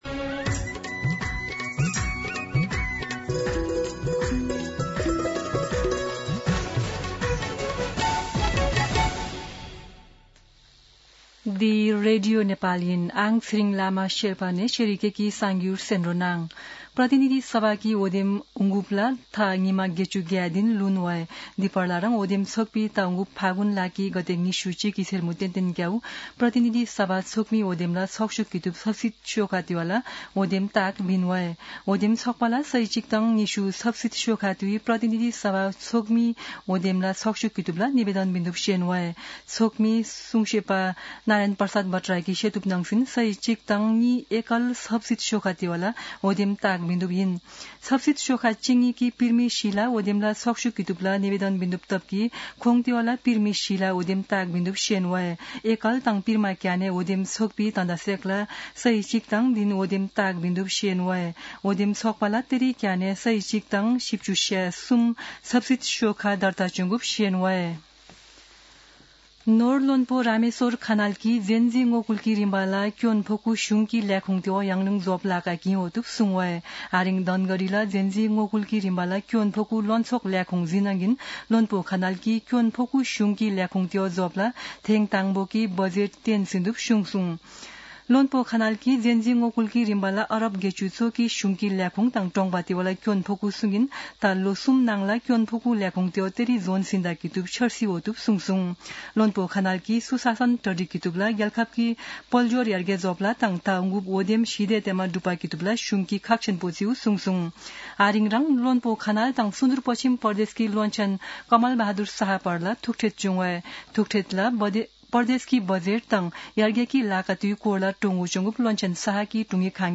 शेर्पा भाषाको समाचार : २२ मंसिर , २०८२
Sherpa-News-8-22.mp3